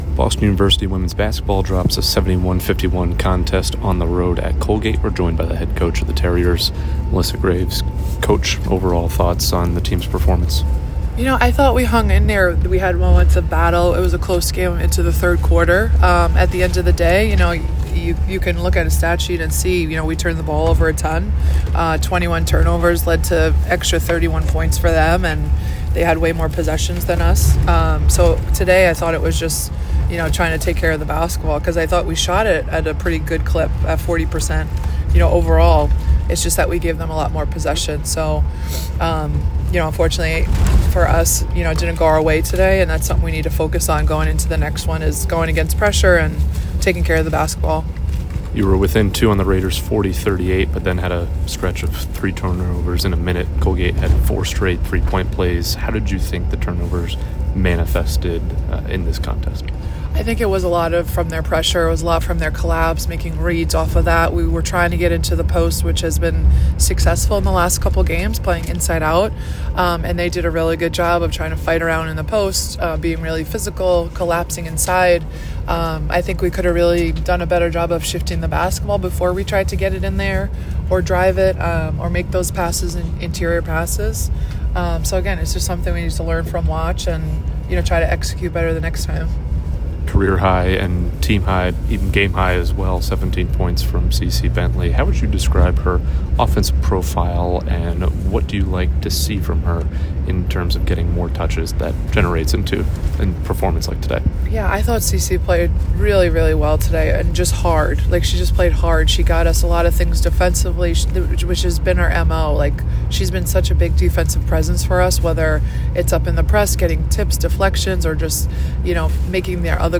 WBB_Colgate_2_Postgame.mp3